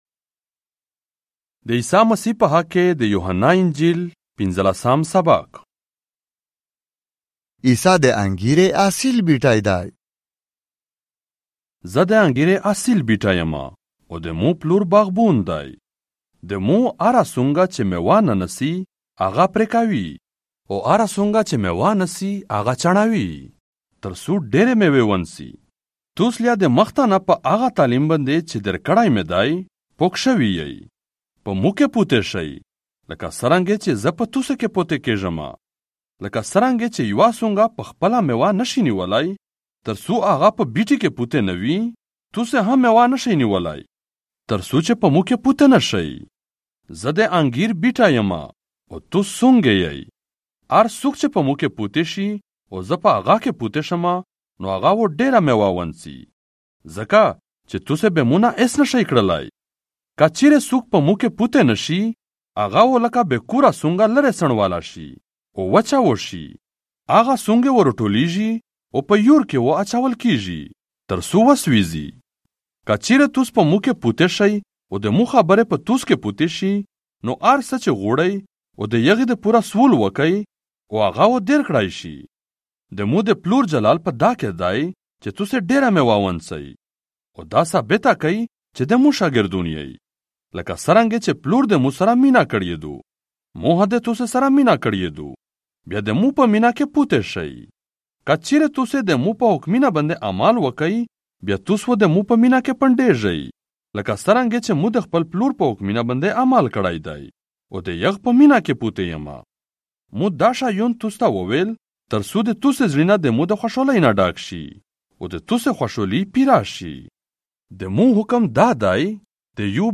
دې عيسیٰ مسيح په حق کې دې يوحنا انجيل - پينځلاسام ساباق، په پشتو ژبه، مرکزي (آډیو) ۲۰۲۵